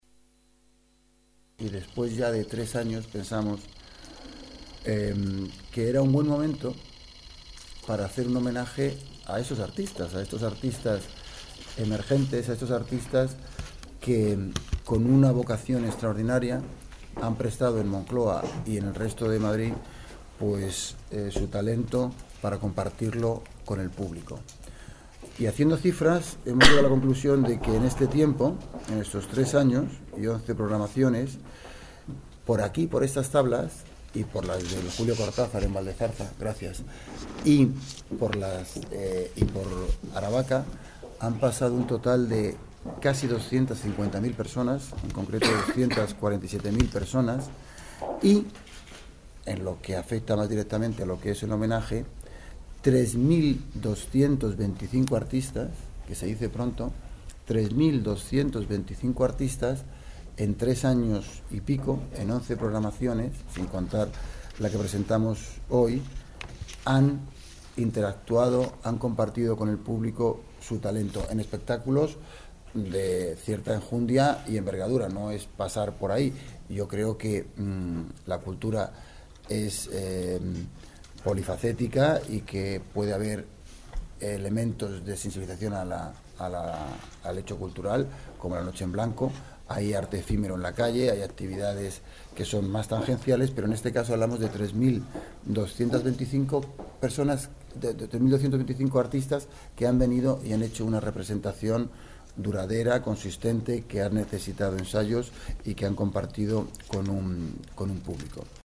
Nueva ventana:Declaraciones del concejal de Moncloa-Aravaca: Distrito 9